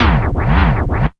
MAD SLIDE.wav